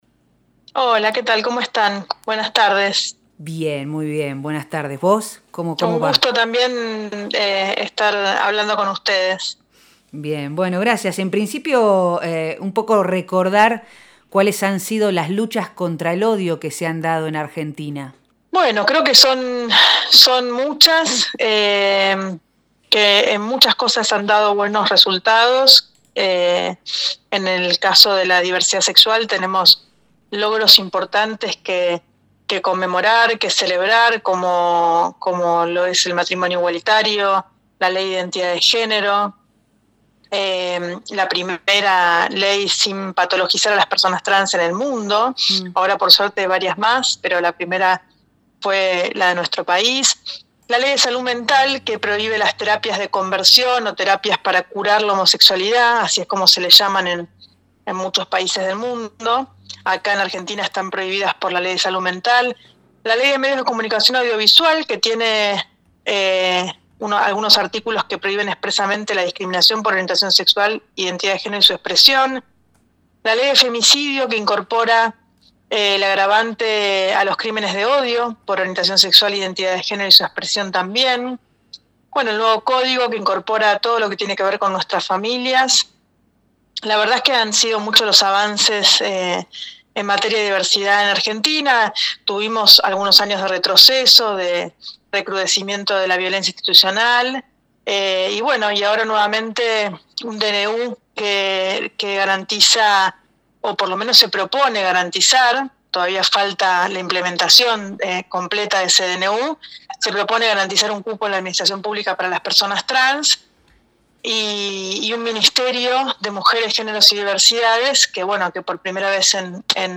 María Rachid es una histórica militante de la comunidad LGBTQI+ de Argentina. En el marco del Día Internacional contra el Odio por Orietación Sexual e Identidad de Género, dialogó con Nosotres les Otres y habló sobre las luchas que se han dado para la conquista de Derechos. También se expresó en relación a los desafíos pendientes y a la importancia de hacer visibles nuestras historias diversas en un sistema en el que impera la heteronorma.
Nosotres les Otres, lunes de 18 a 20 por FM Horizonte 94.5